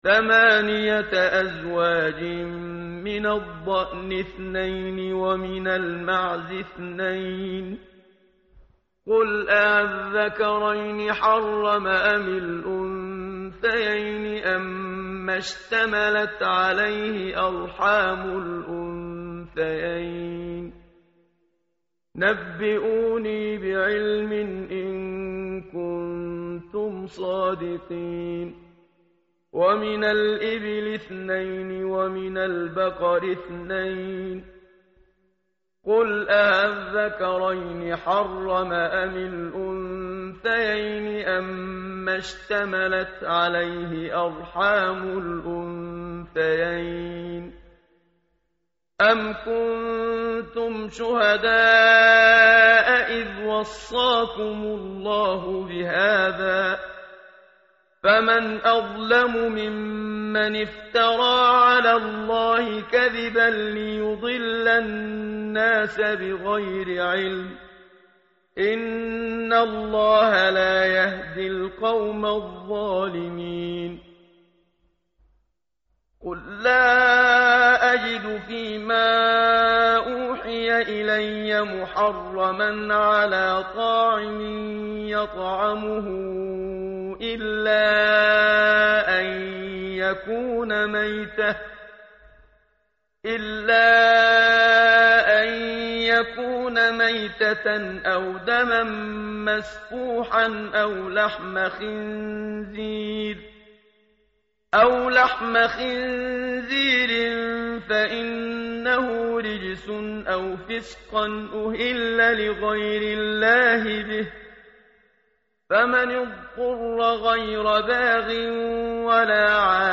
متن قرآن همراه باتلاوت قرآن و ترجمه
tartil_menshavi_page_147.mp3